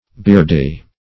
Search Result for " beardie" : The Collaborative International Dictionary of English v.0.48: Beardie \Beard"ie\ (b[=e]rd"[y^]), n. [From Beard , n.]